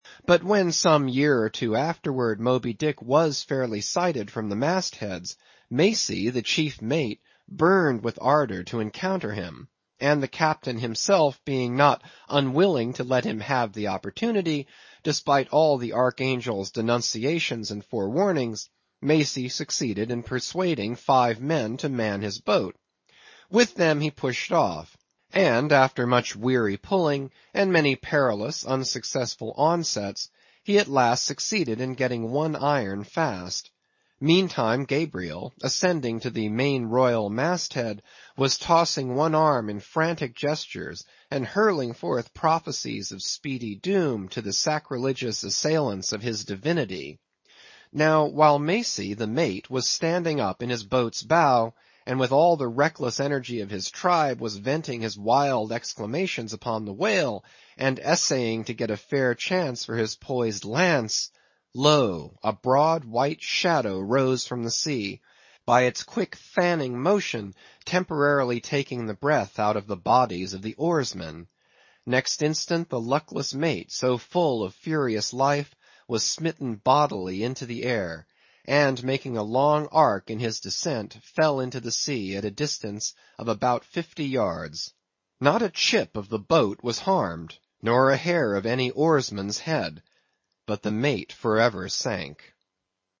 英语听书《白鲸记》第649期 听力文件下载—在线英语听力室